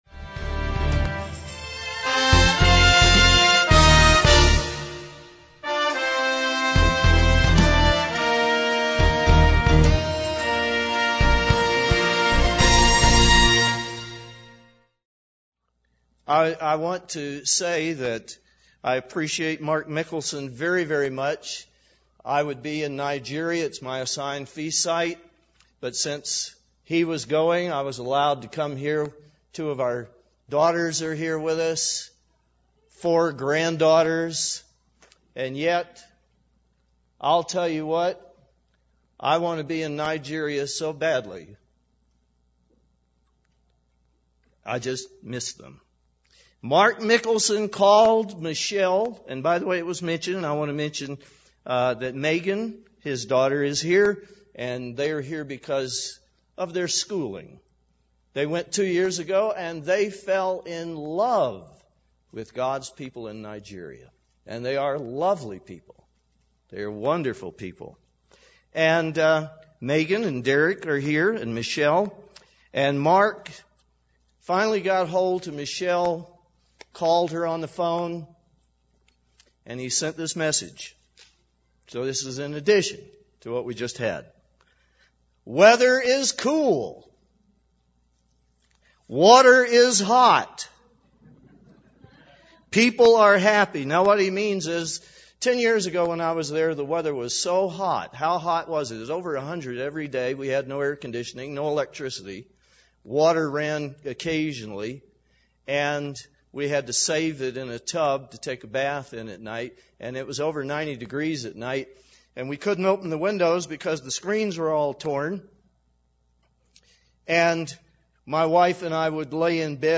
This sermon was given at the Steamboat Springs, Colorado 2006 Feast site.